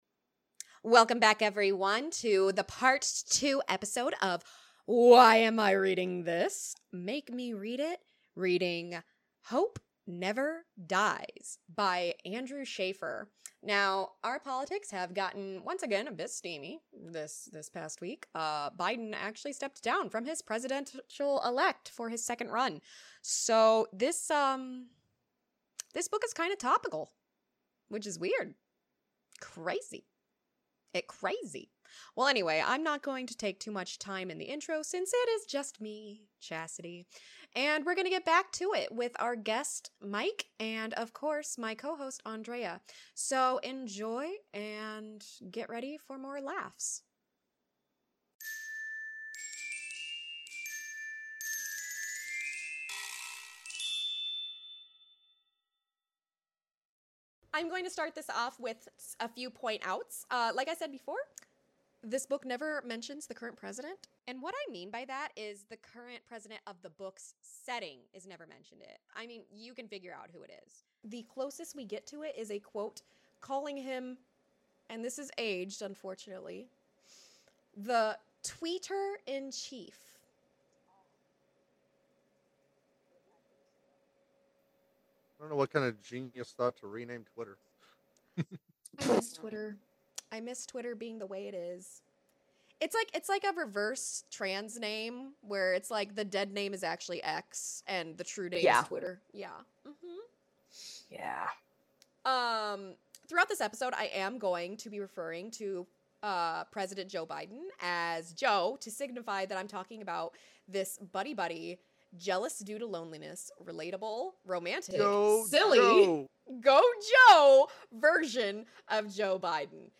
Please forgive the occasional strange audio mishaps in the video.